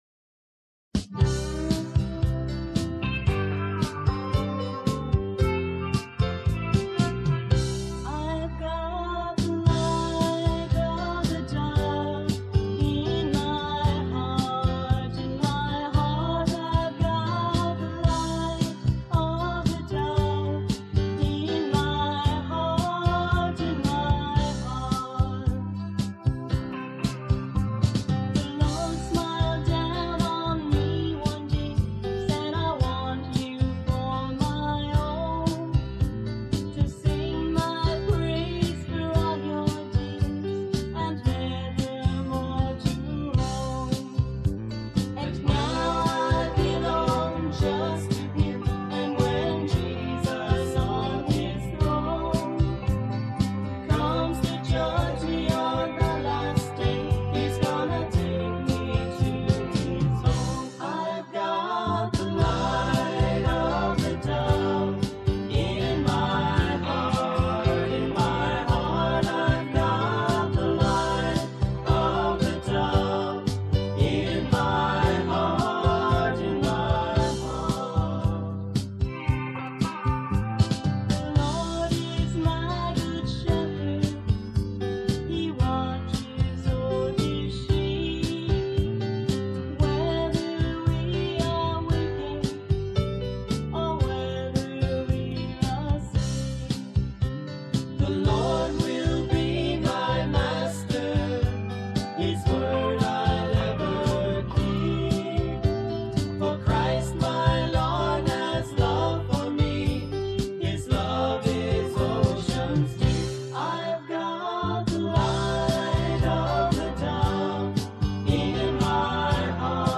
Synthesizer on all selections
Bass guitar on all selections
String and synthesizer arrangements on all selections
Drums and percussion on all selections